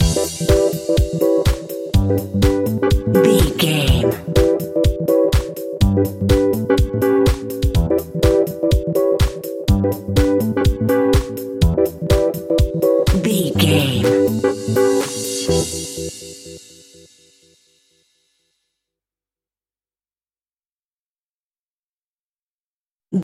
Aeolian/Minor
groovy
uplifting
energetic
funky
bass guitar
drums
electric piano
synthesiser
funky house
disco
upbeat
instrumentals